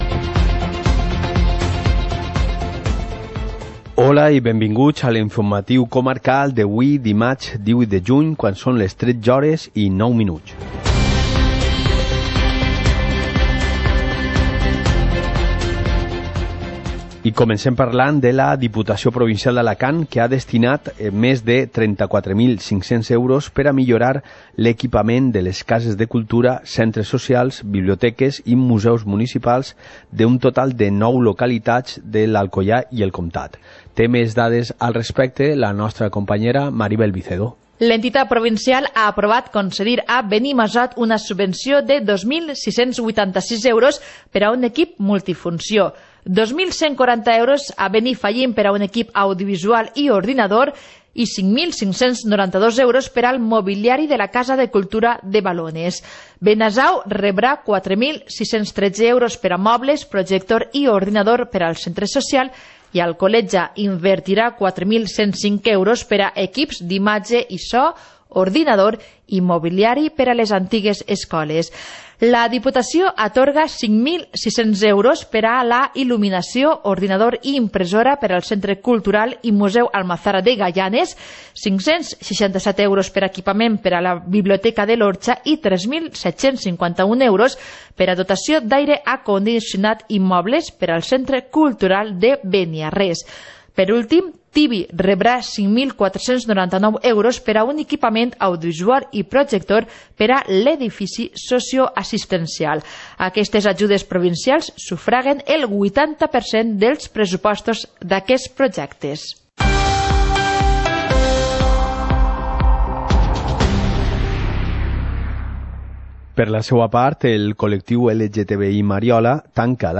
Informativo comarcal - martes, 18 de junio de 2019